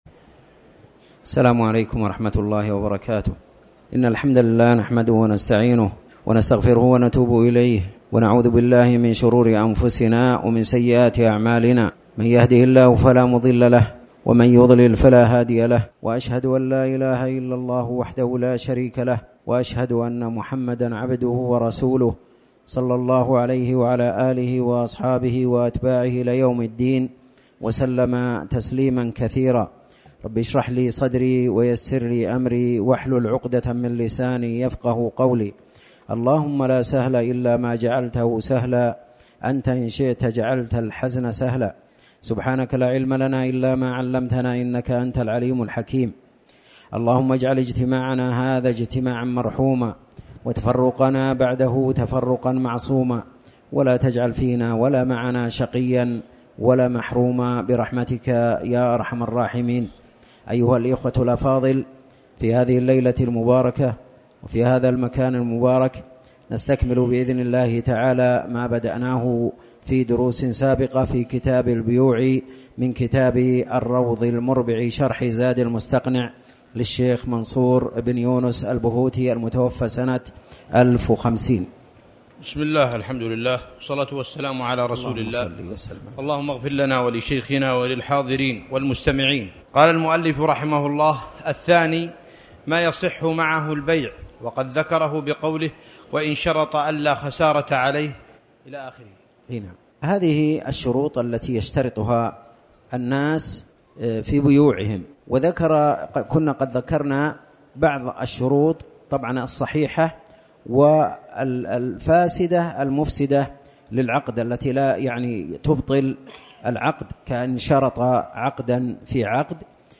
تاريخ النشر ١٠ رجب ١٤٣٩ هـ المكان: المسجد الحرام الشيخ